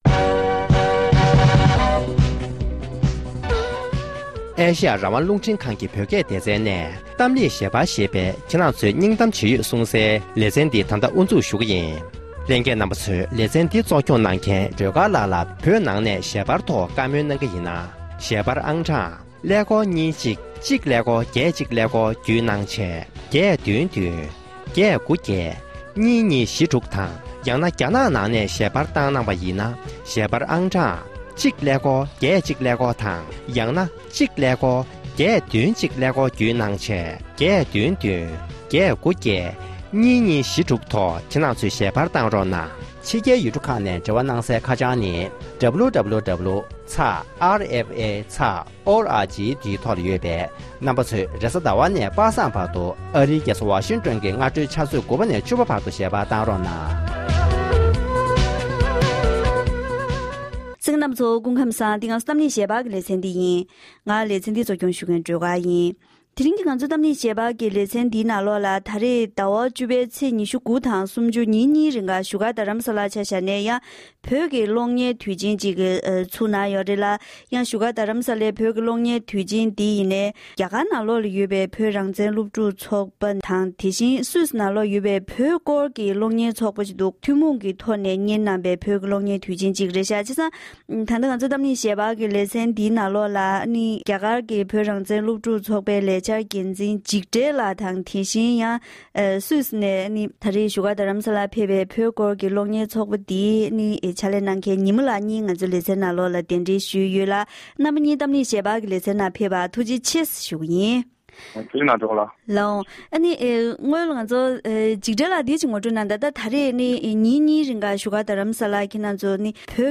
འབྲེལ་ཡོད་མི་སྣའི་ལྷན་གླེང་མོལ་གནང་བར་གསན་རོགས༎